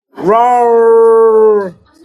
Raowr Sound Button - Free Download & Play